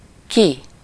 Ääntäminen
US : IPA : [ˈaʊt]